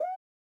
ORG Woop FX.wav